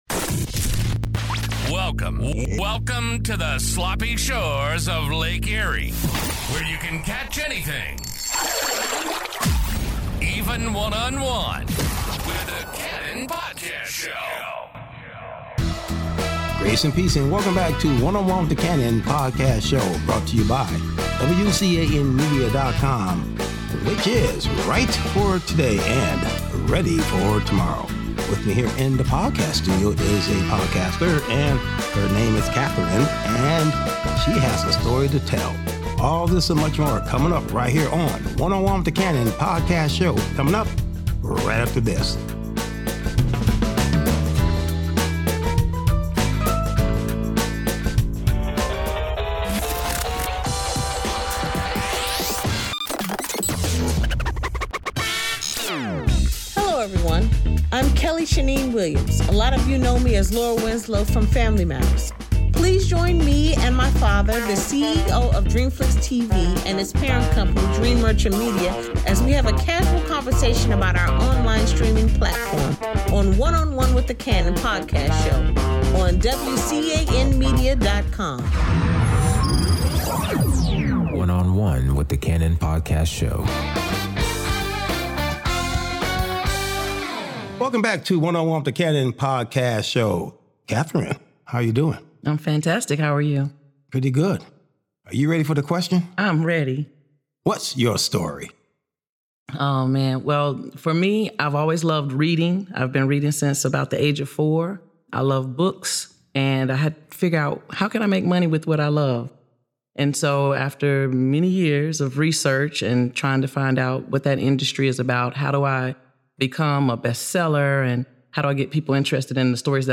An urban talk show examining the issues within and without urban community, that affects the world we live in.